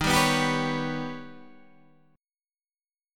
D# Augmented 7th